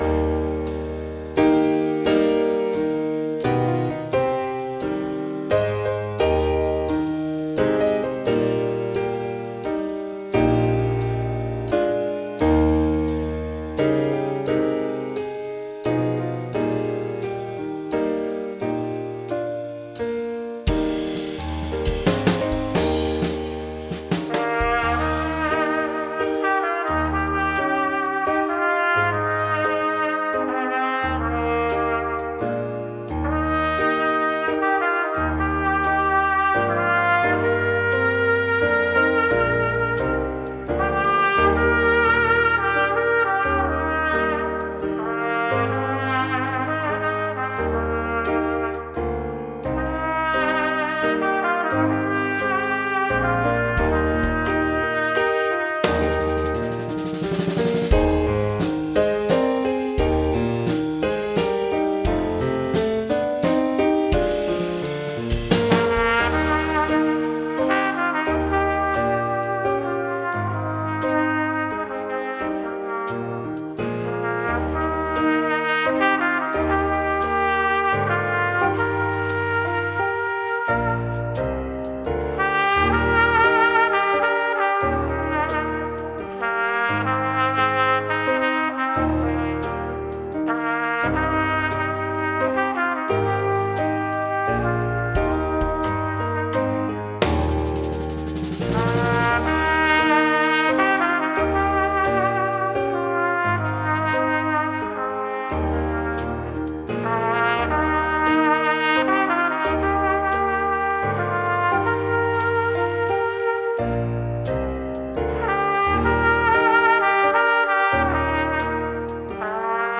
TROMBA SOLO • ACCOMPAGNAMENTO BASE MP3
Base - Concerto